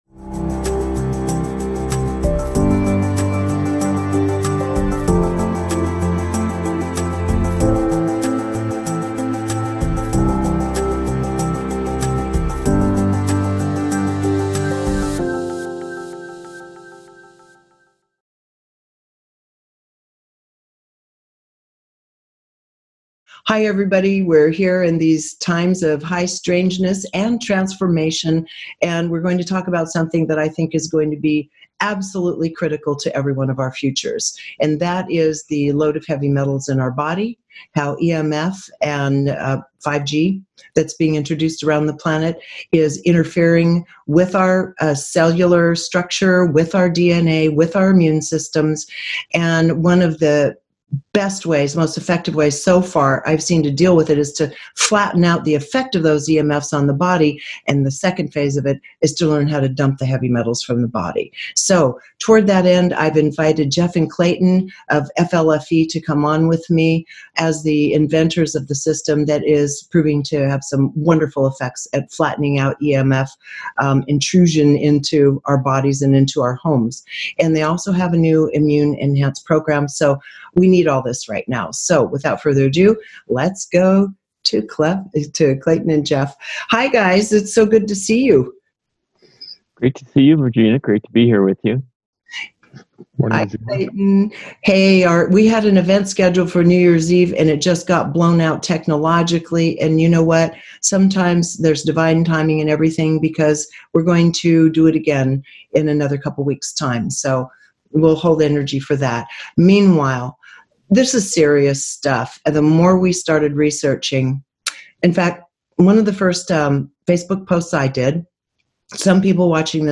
There is such an incredible amount of helpful information to sort through that I am including both an interview and a separate link to include for your own research.